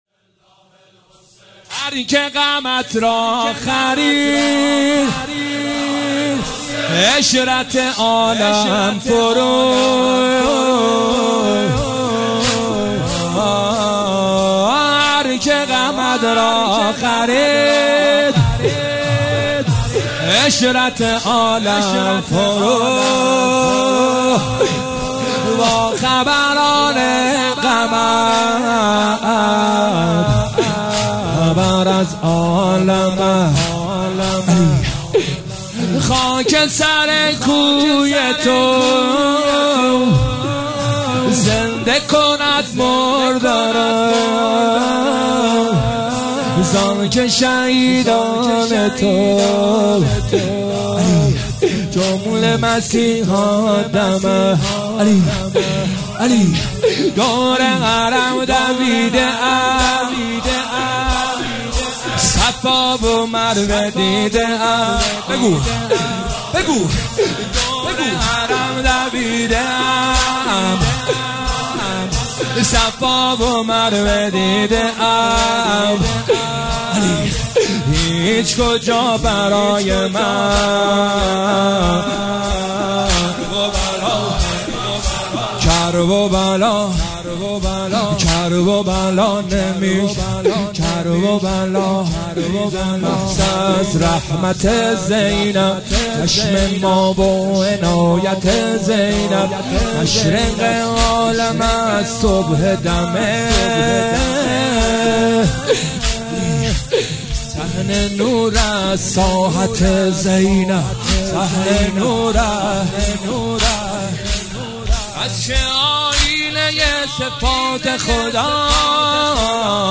مداحی واحد
جلسه هفتگی 20 اردیبهشت 1404